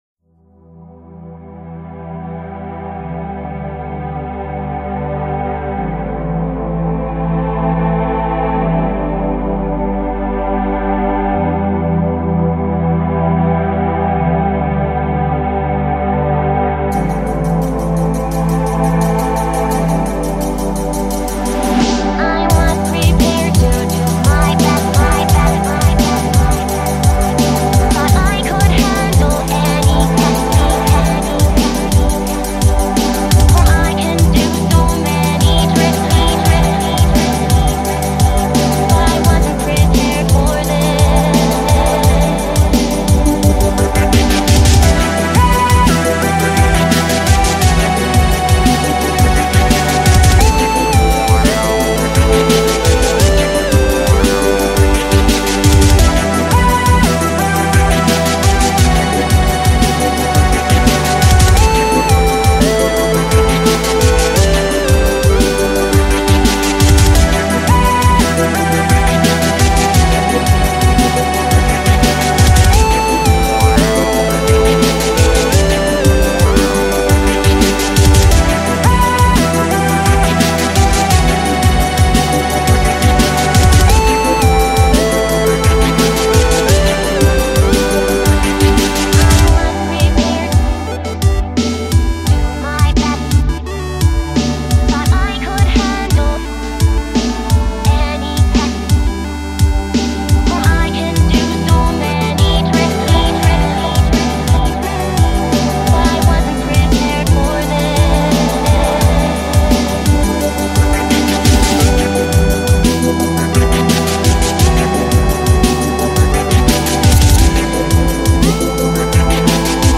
This is my first venture into IDM/Chill